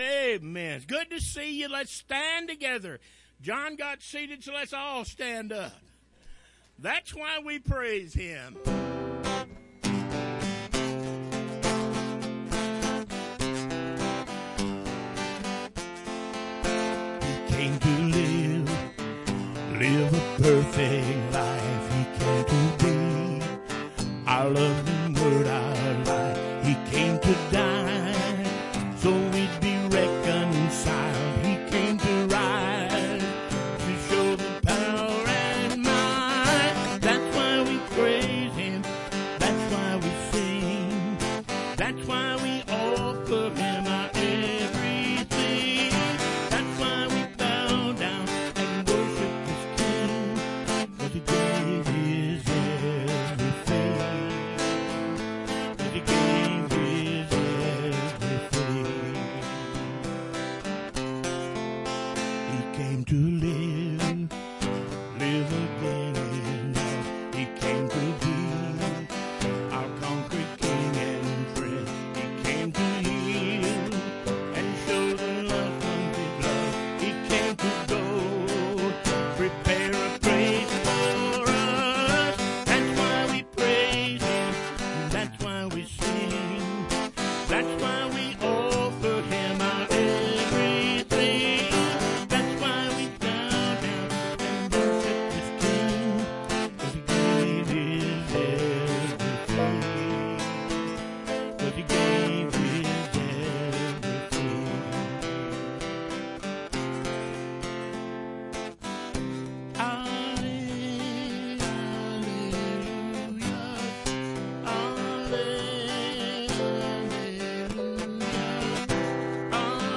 Bible Text: Leviticus 8:18-24 | Preacher: